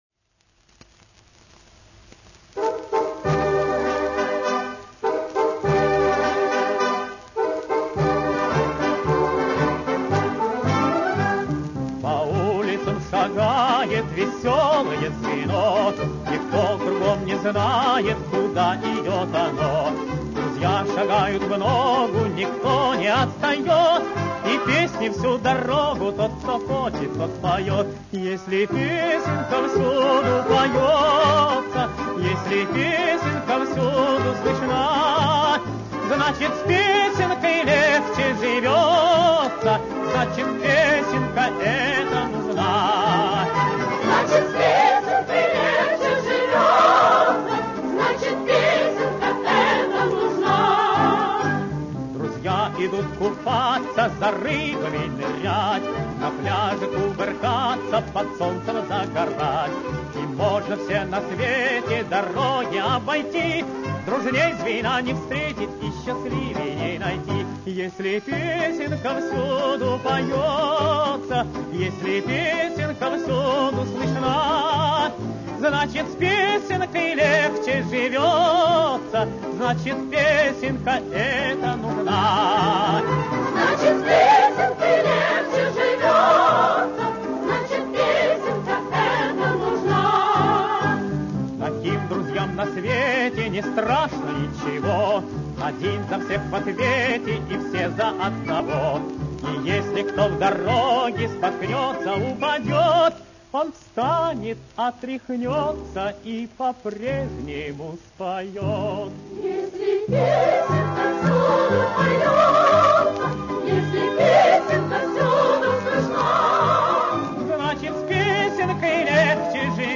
Жизнерадостная советская песня.